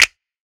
Percs
MZ Snap [Metro #2].wav